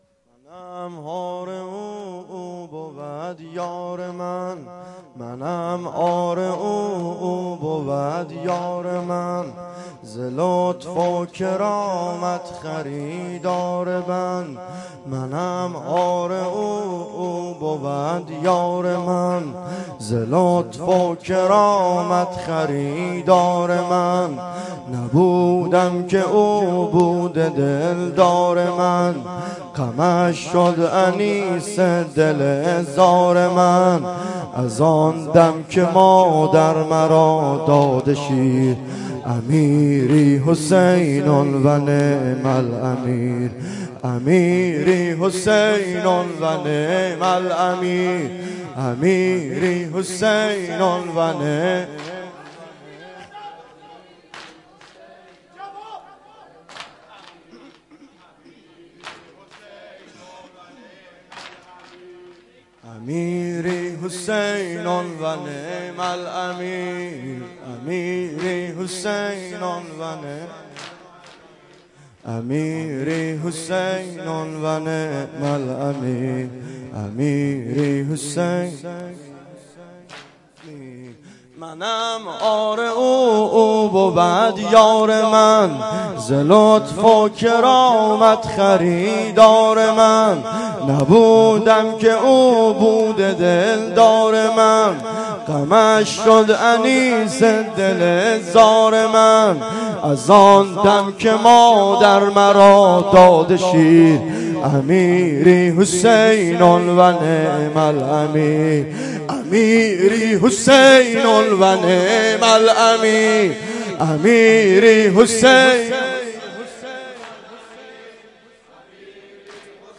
شب سوم ماه محرم